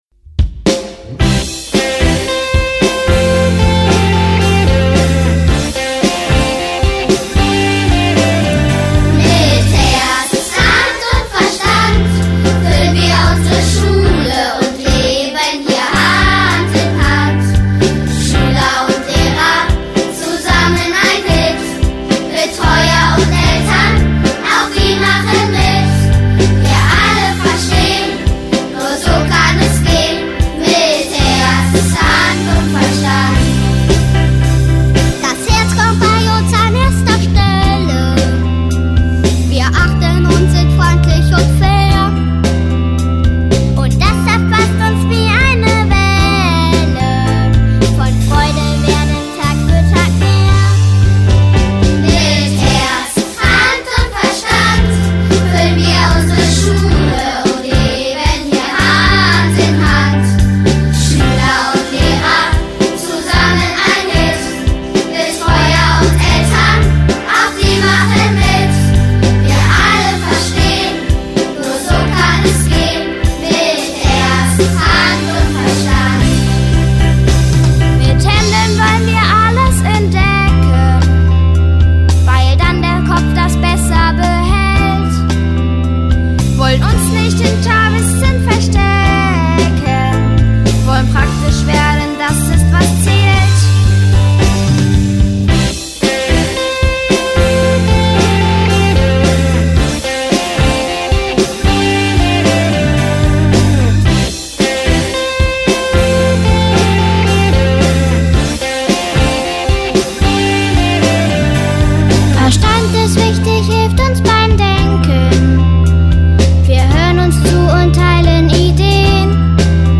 Unser Schullied handelt genau davon. Es wurde mit Kindern unserer Schule aufgenommen und wird bei allen Schulveranstaltungen gemeinsam gesungen.